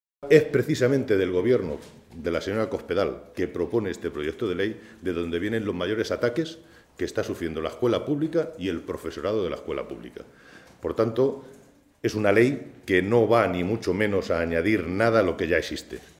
Santiago Moreno, diputado regional del PSOE de Castilla-La Mancha
Cortes de audio de la rueda de prensa